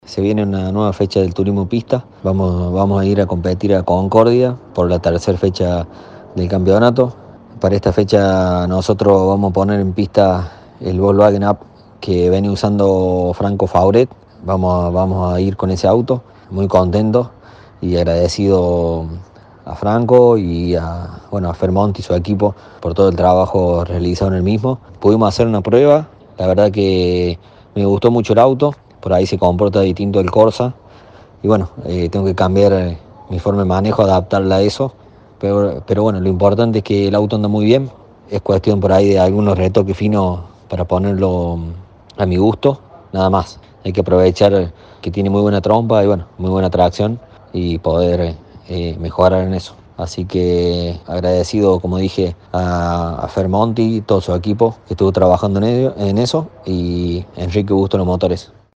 Declaración del piloto